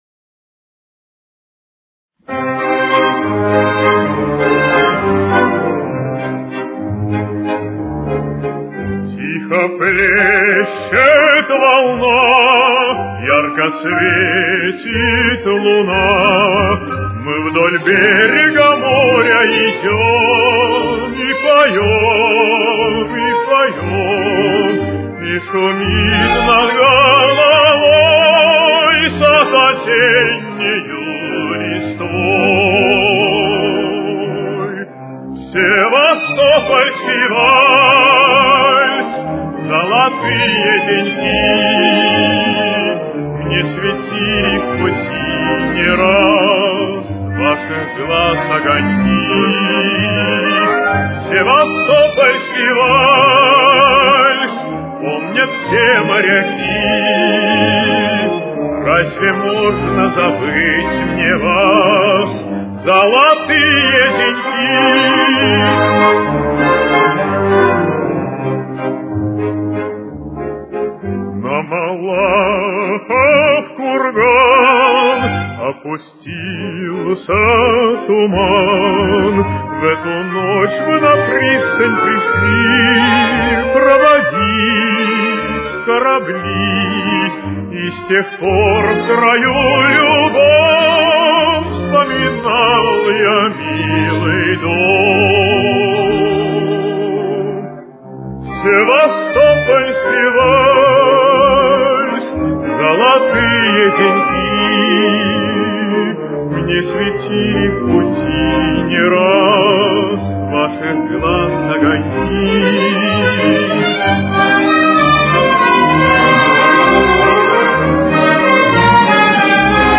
с очень низким качеством
До минор. Темп: 188.